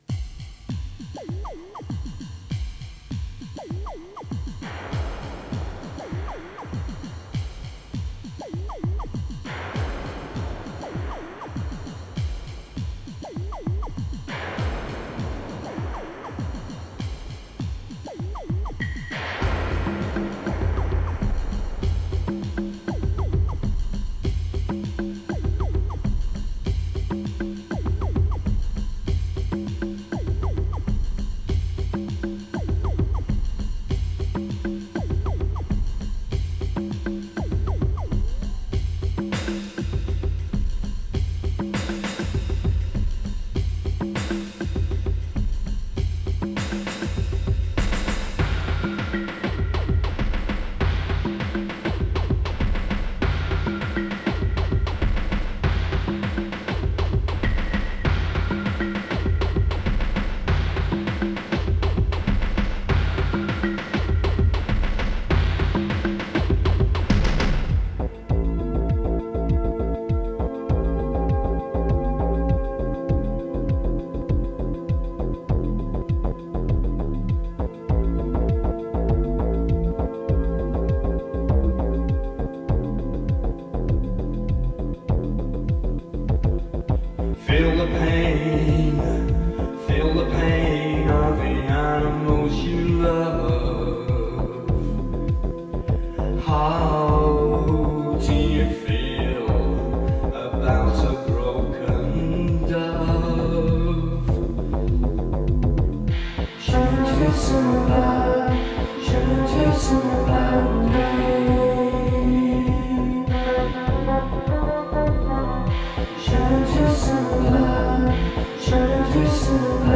lead vocals, guitar, midi-synth and drum programming
flute, percussion, harmony vocals